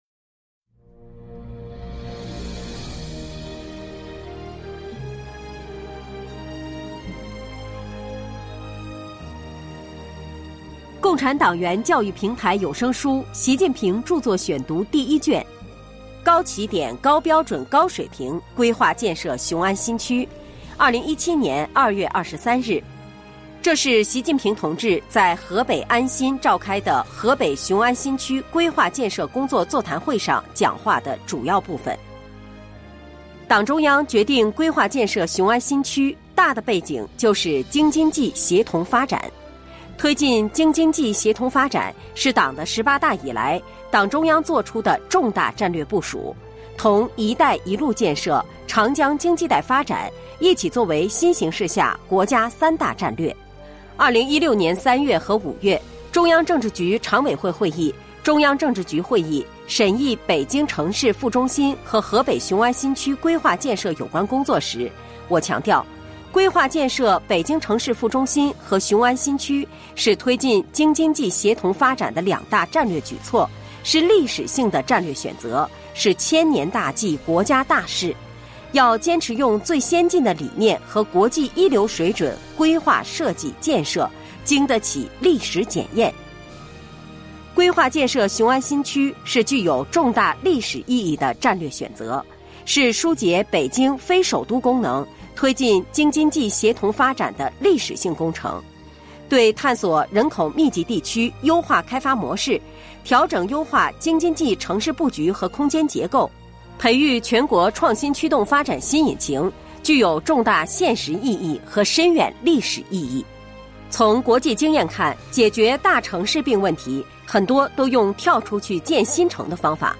主题教育有声书 《习近平著作选读》第一卷（83）.mp3